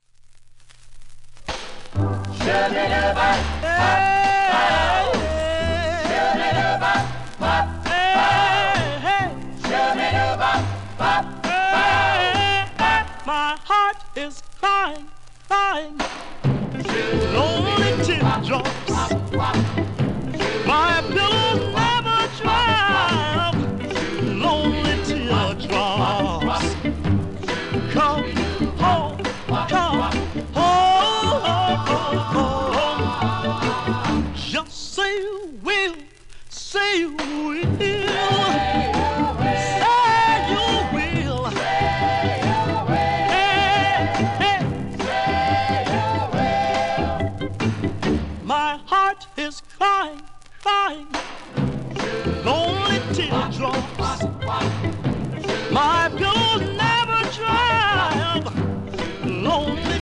w/コーラス、オーケストラ
1958年録音